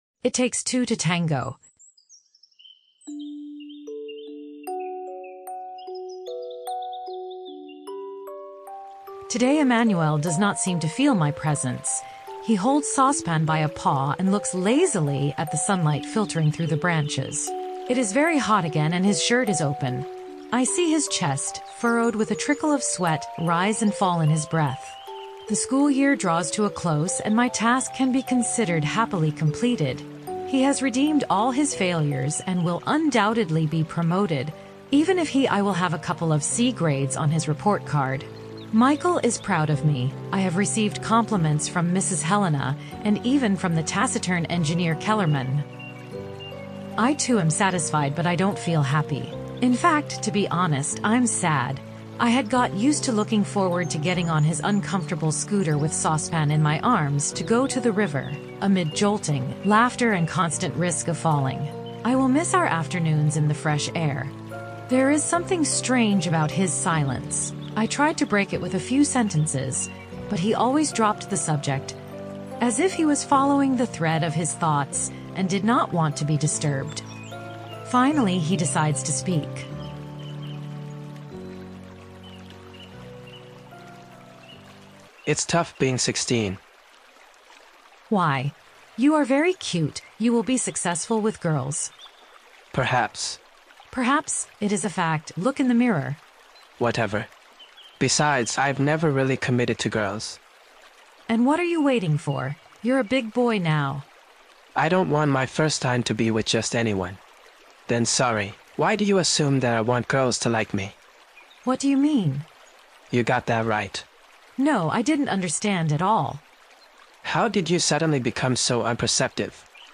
We are re-proposing during the Christmas break a key episode from Season 1, completely remade with new AI.